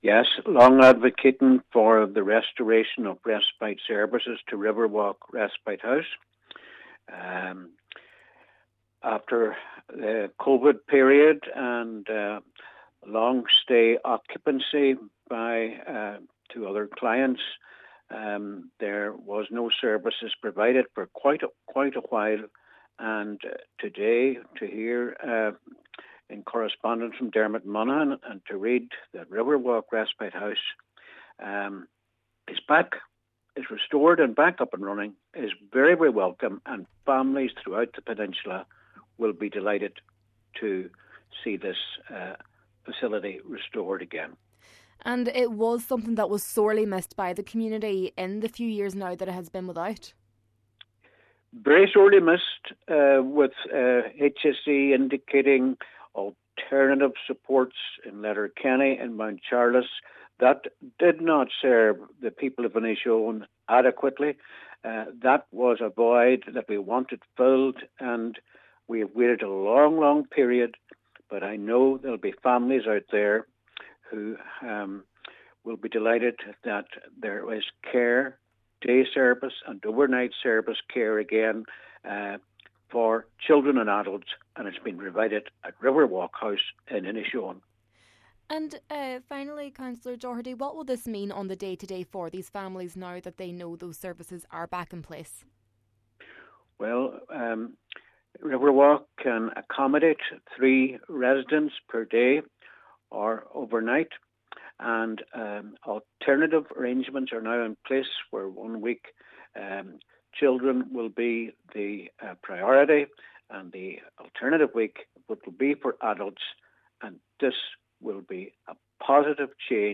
Councillor Albert Doherty, who has long campaigned for the service’s reinstatement, described it as a good day for the peninsula: